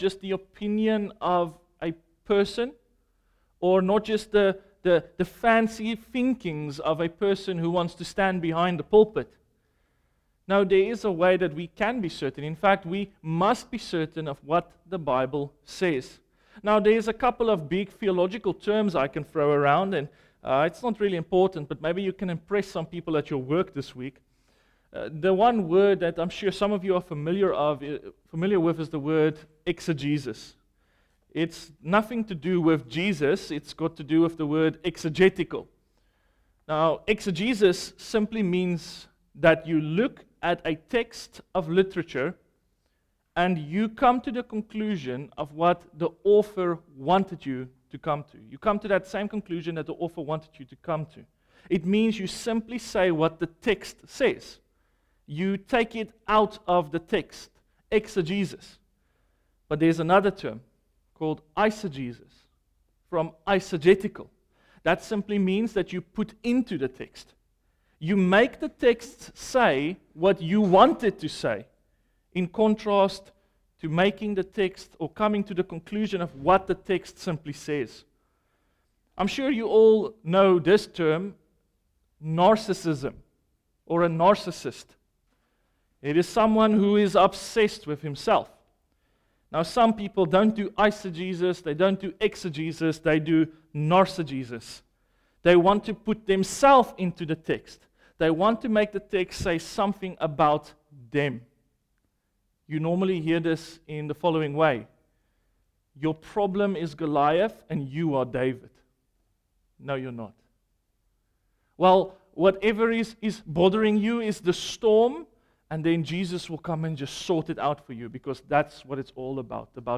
Jesus calms the storm in your life? - Calvary Baptist Church Richards Bay